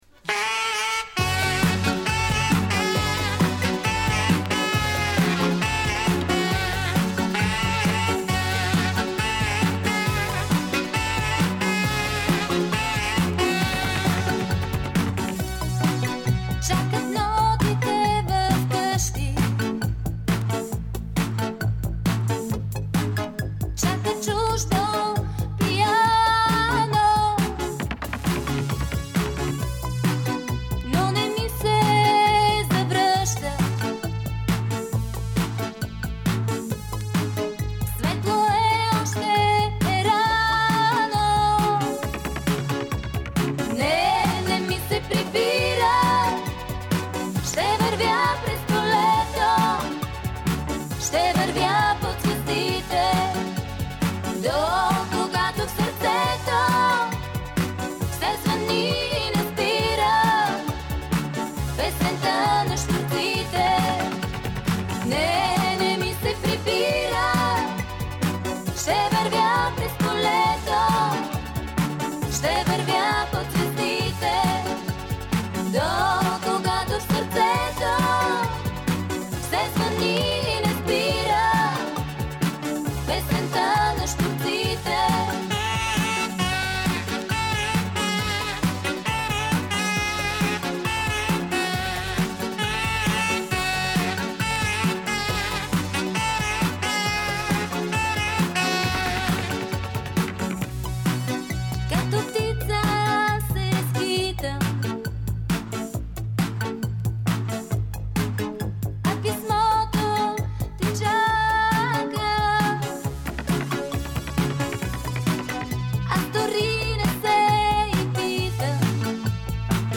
Записана с пластинки